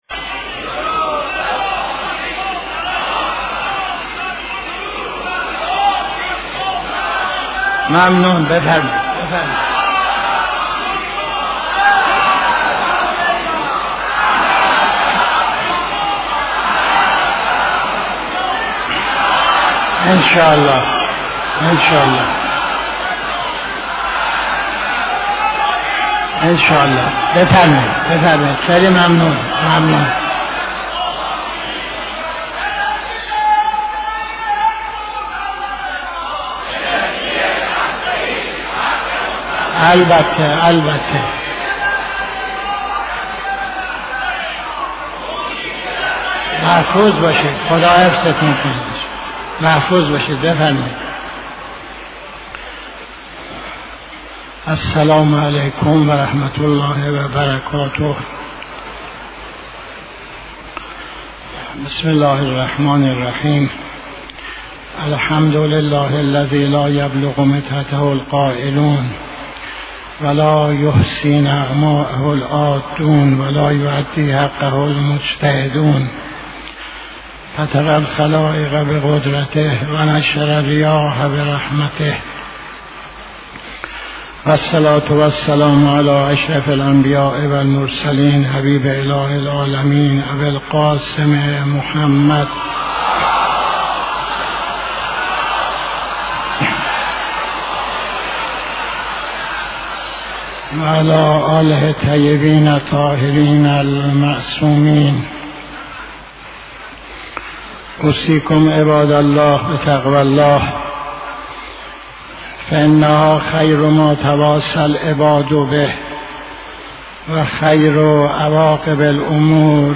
خطبه اول نماز جمعه 22-02-85